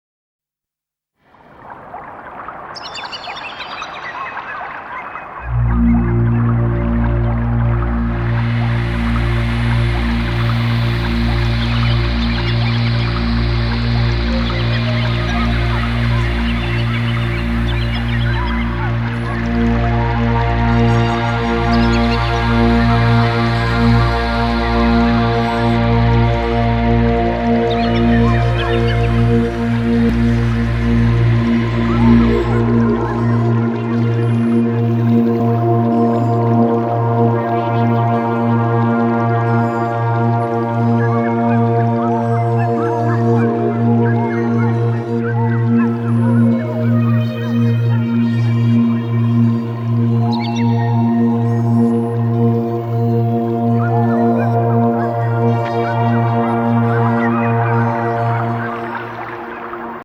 チベット仏教のお経は、まるで美しい歌のように聴こえてきます。